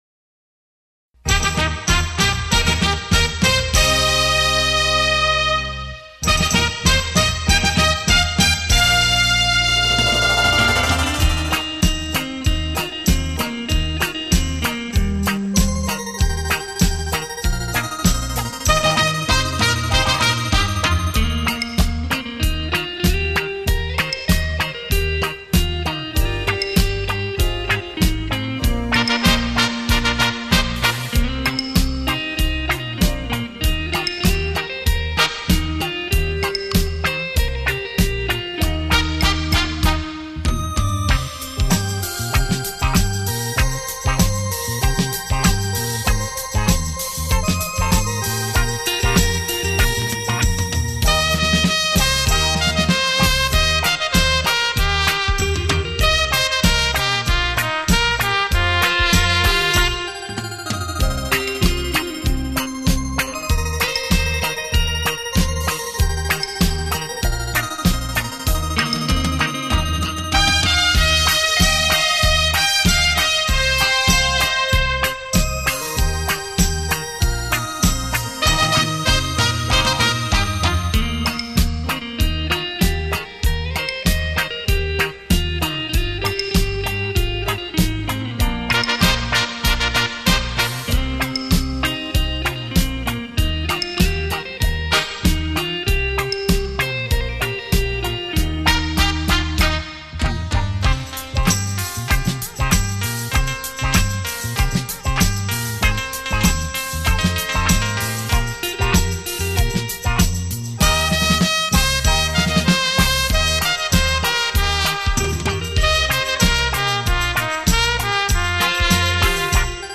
唱片风格：沙龍雷射效果
悠悠缠绵的电子琴音乐